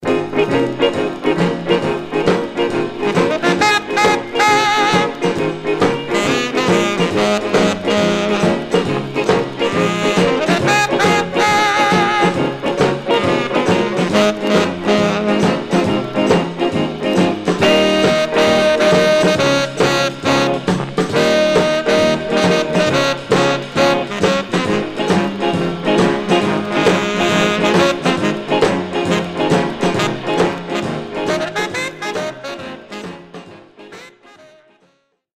Stereo/mono Mono
R&B Instrumental Condition